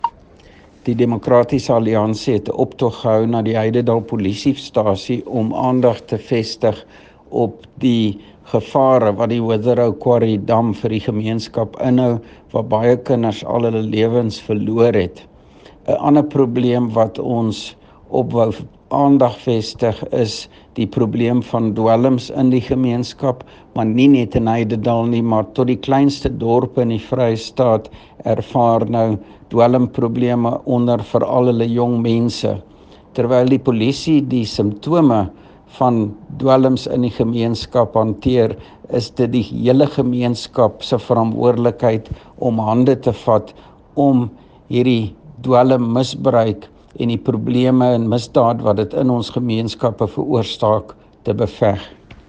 Afrikaans soundbites by Dr Roy Jankielsohn MPL.